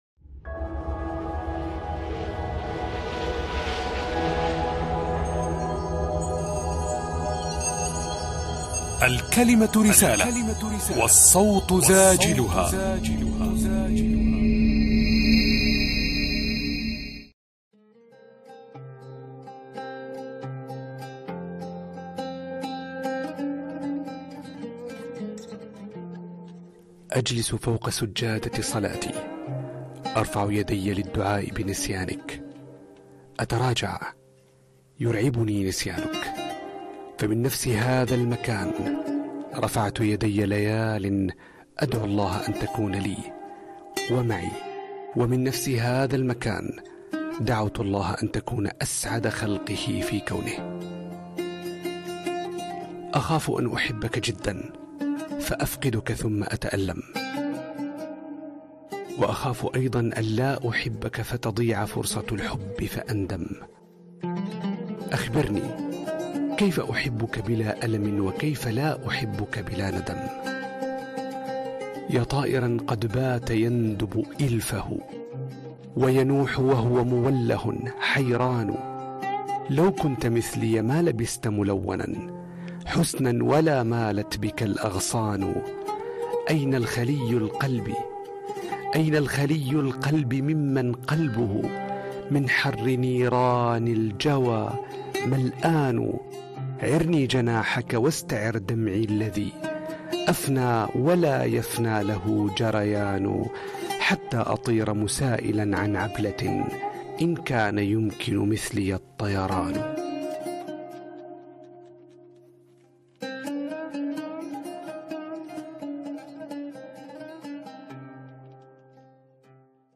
التعليق الصوتي Voice over
اللون العاطفي الوجداني
شبيه باللون الشعري من حيث الخصائص إلا أنه يتطلب مزيداً من استشعار النص لأنه يركز على جانب واحد وهو العاطفة ويفضل فيه الصوت الهادئ.